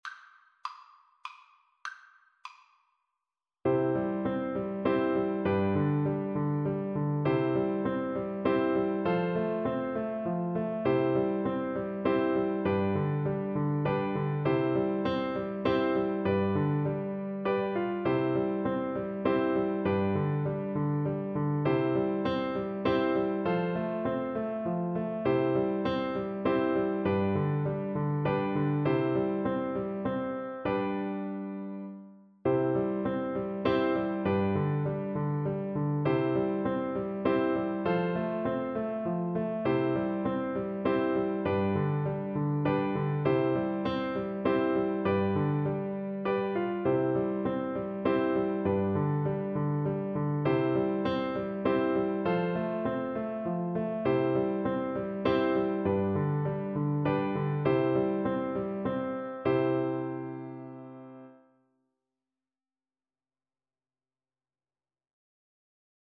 Flute version
3/4 (View more 3/4 Music)
Moderato
Traditional (View more Traditional Flute Music)